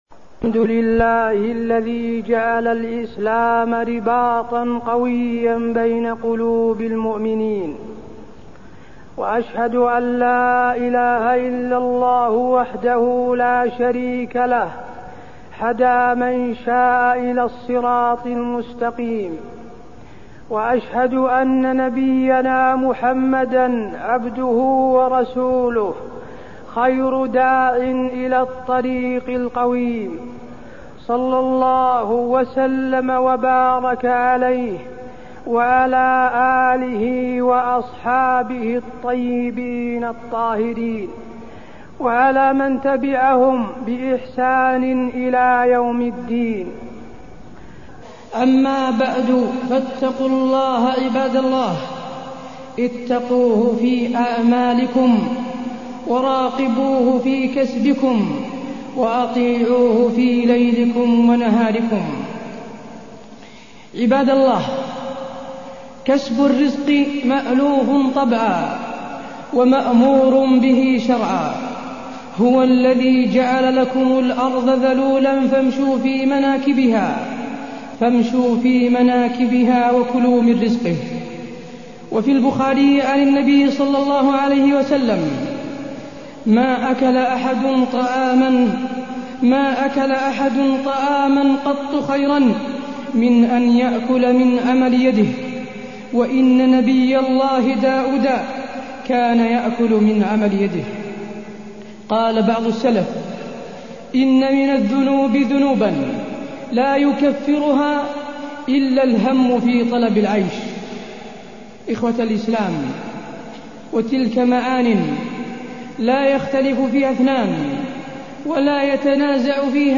تاريخ النشر ٤ جمادى الأولى ١٤٢١ هـ المكان: المسجد النبوي الشيخ: فضيلة الشيخ د. حسين بن عبدالعزيز آل الشيخ فضيلة الشيخ د. حسين بن عبدالعزيز آل الشيخ الرزق والكسب الحلال